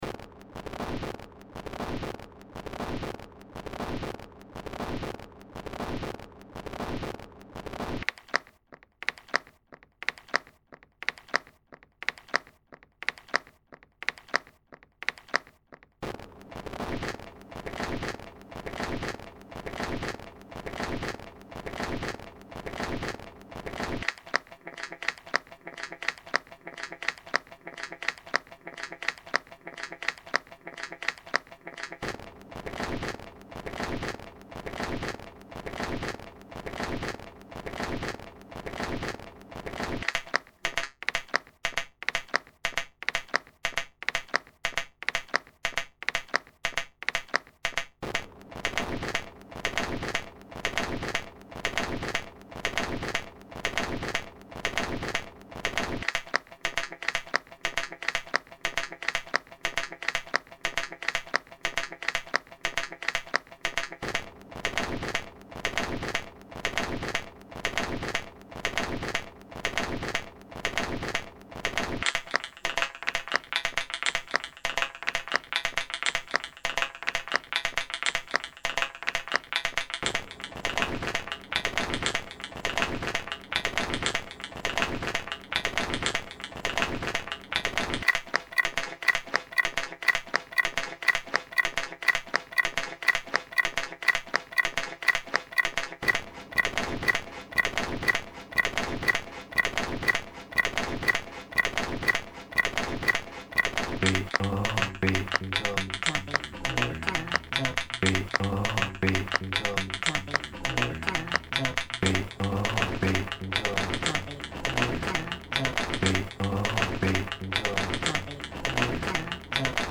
is a collection of lo-fi electronic dance tracks.
This is raw clicks'n'cuts for the new millennium.